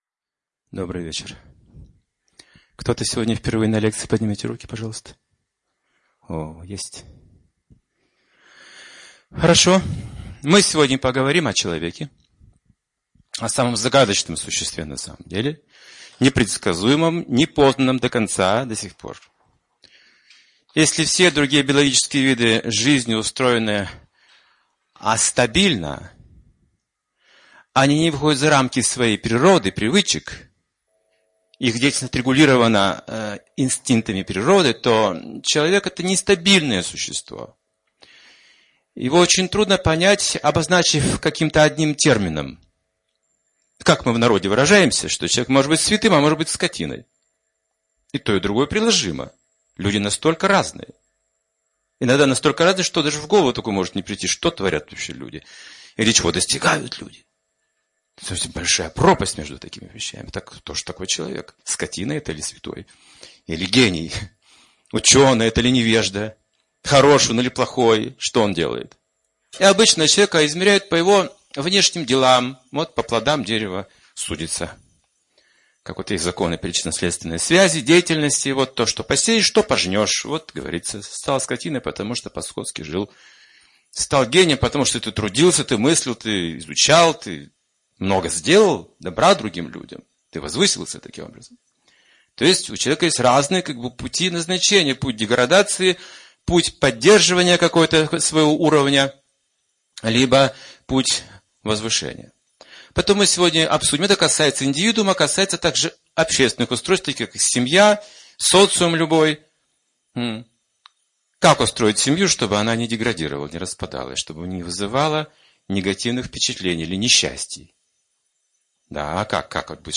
Лекция о человеке.